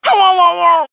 One of Funky Kong's voice clips in Mario Kart Wii